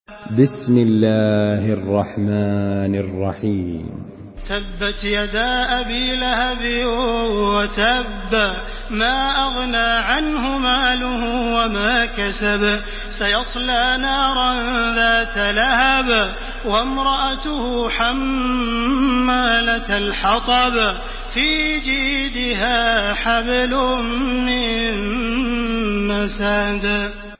تحميل سورة المسد بصوت تراويح الحرم المكي 1429
مرتل حفص عن عاصم